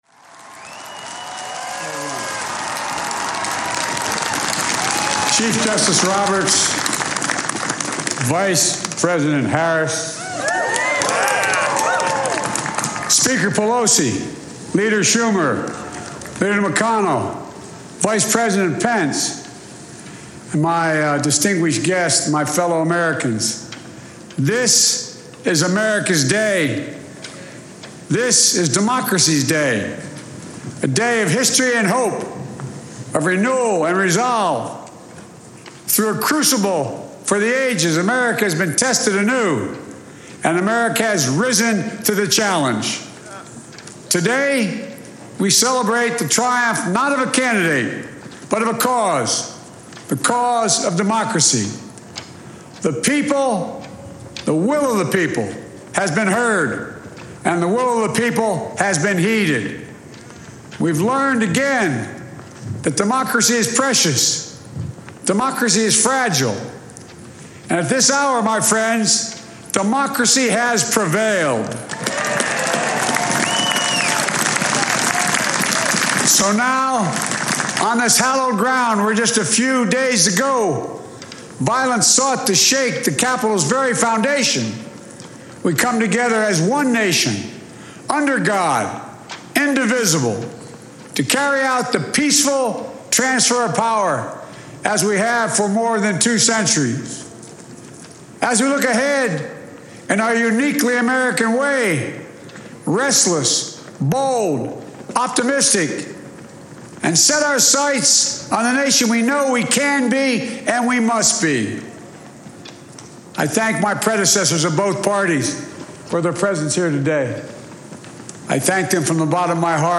B iden Presidential Inaugural Address delivered 20 January 2021, U.S. Capitol Building, Washington, D.C.
joebideninauguraladdressARXE.mp3